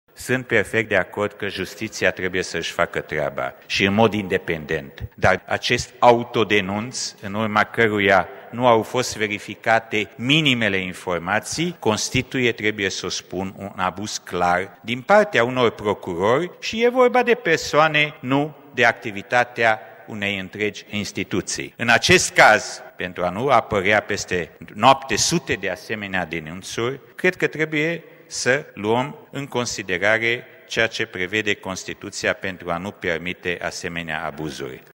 Borbely a susţinut, înaintea votului, că ar fi victima unui abuz din partea procurorilor DNA: